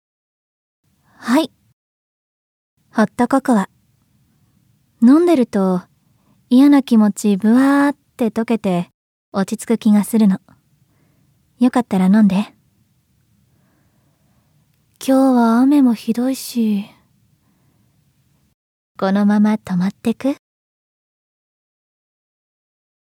◆クールなお姉さん◆